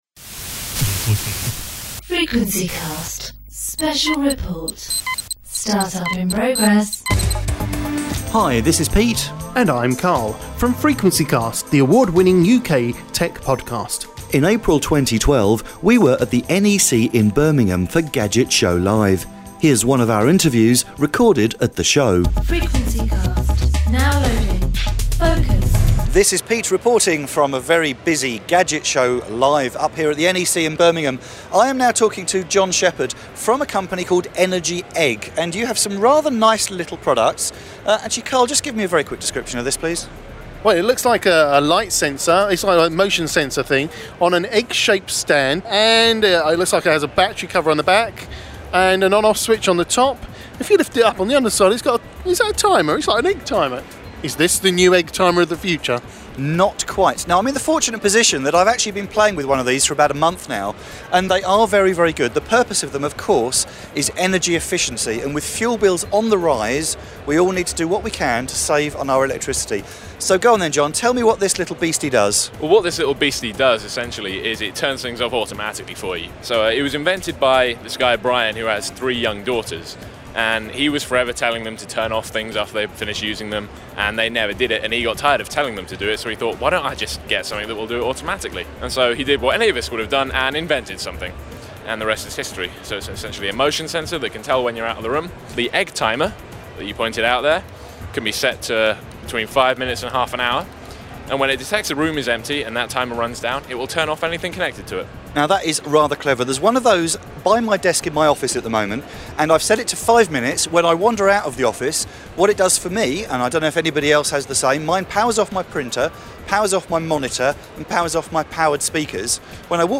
EnergyEGG Interview
Our interview with the EnergyEGG team, recorded at Gadget Show Live in Birmingham